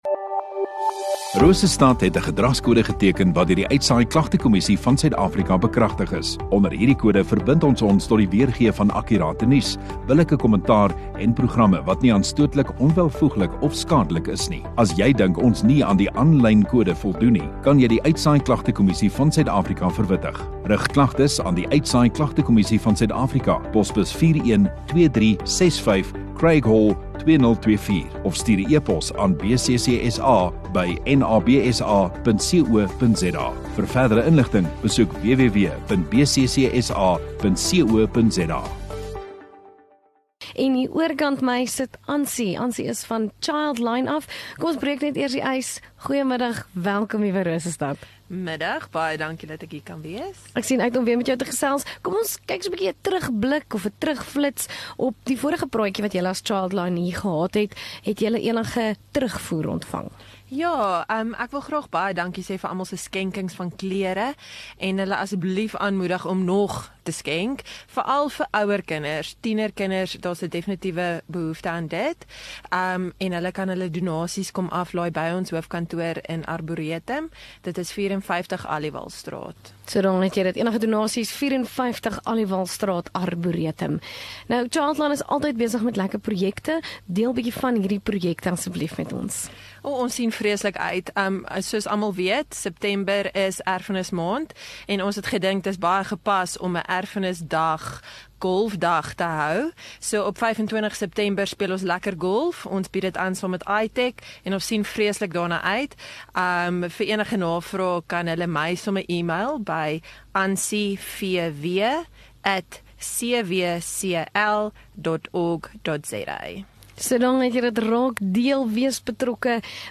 Radio Rosestad View Promo Continue Radio Rosestad Installeer Gemeenskap Onderhoude 17 Sep Childline